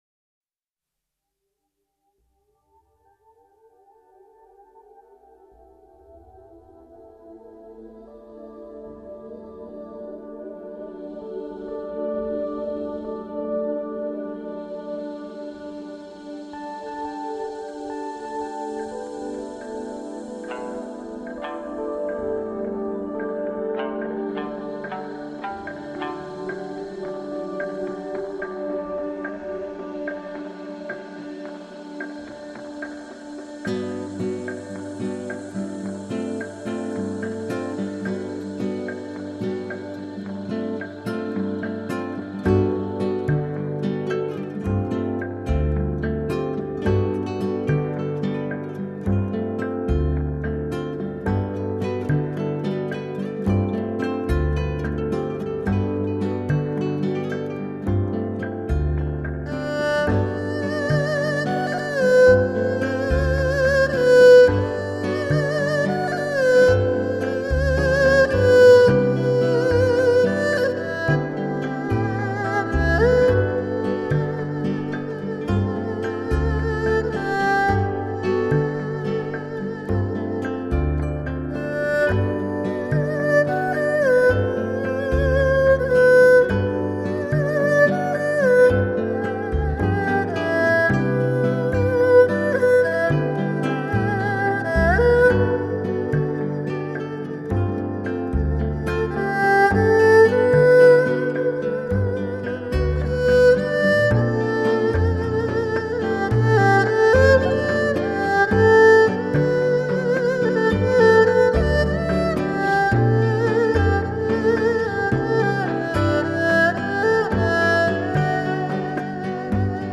二胡也被称做了东洋的小提琴。